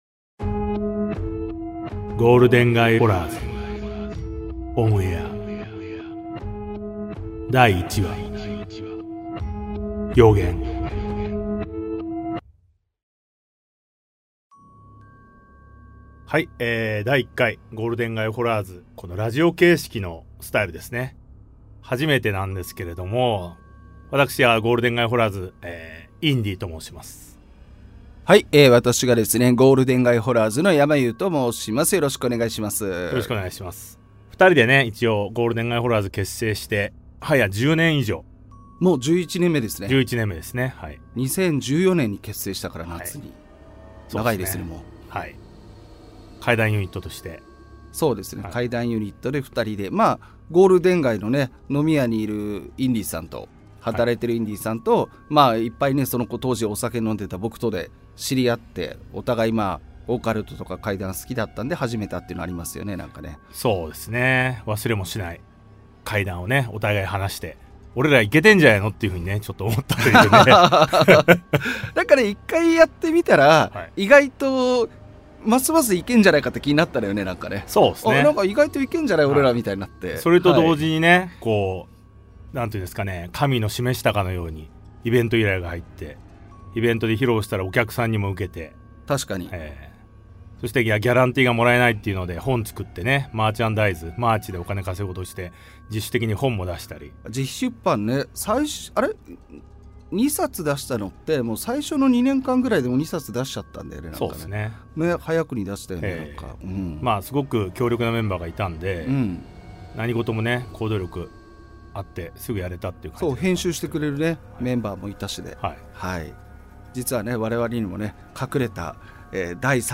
対談形式のホラー番組。